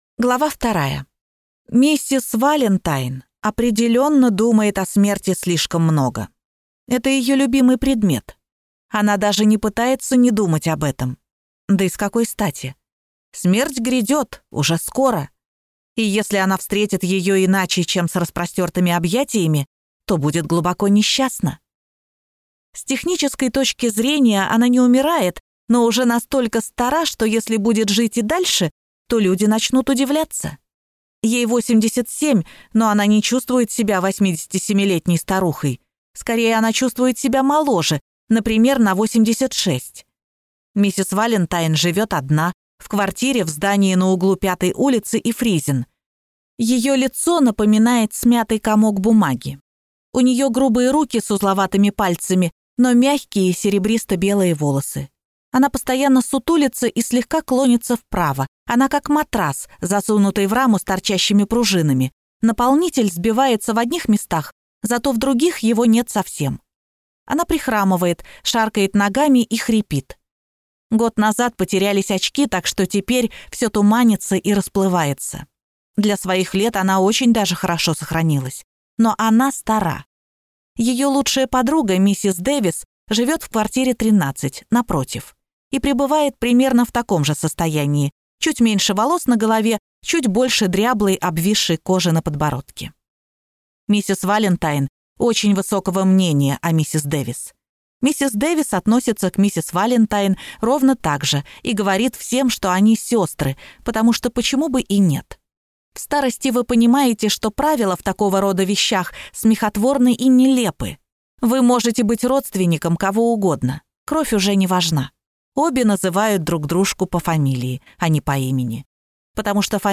Аудиокнига Валенсия и Валентайн | Библиотека аудиокниг